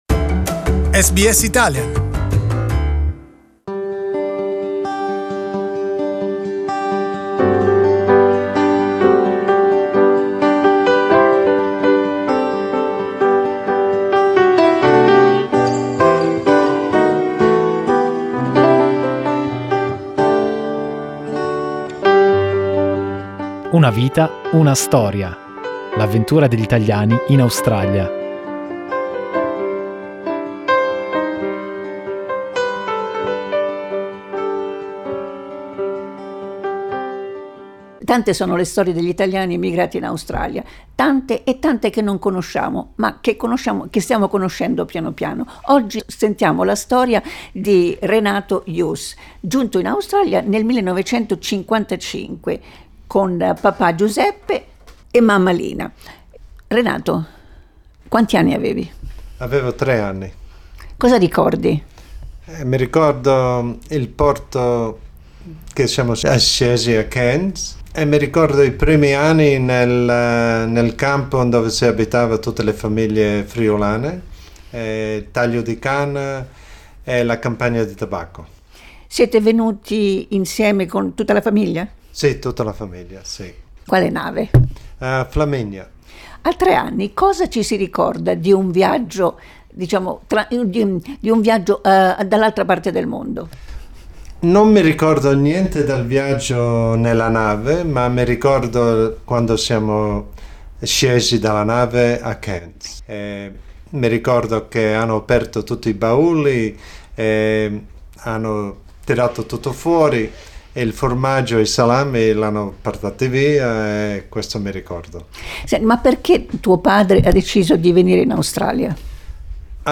In our interview he tells about the difficult first few years of school, when he was called names and beaten by local kids for being a foreigner .